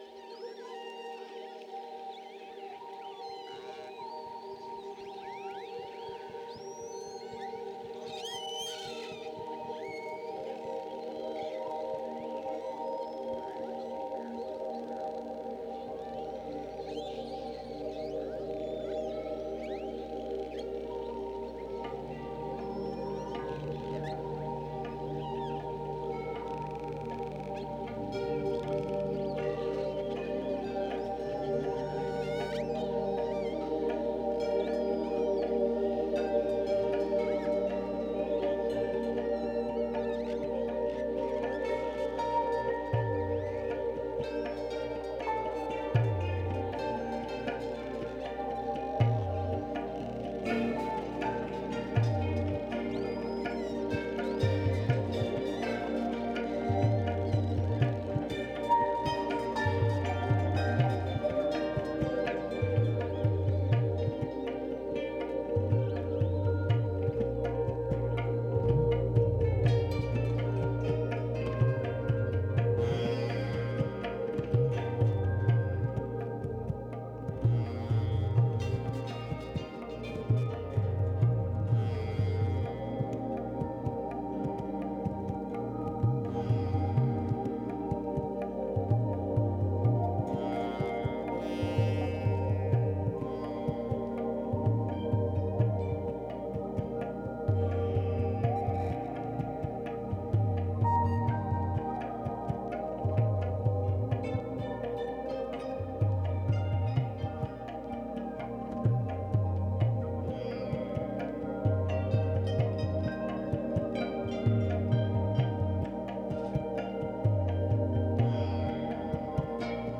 Genre: Dub, Downtempo, Tribal.